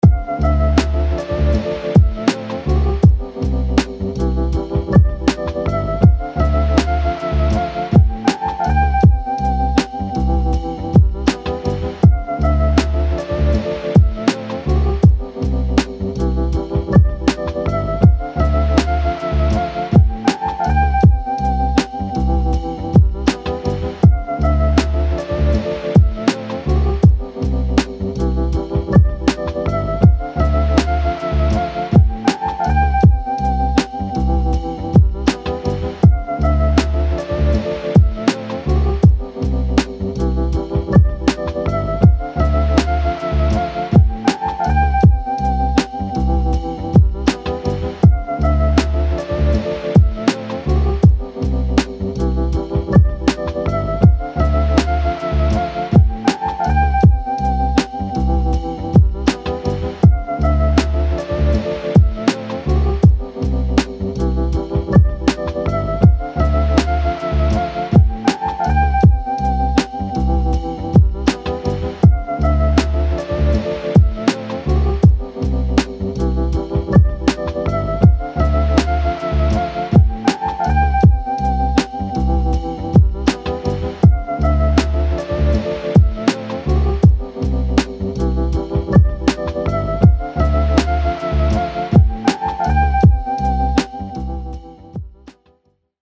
noise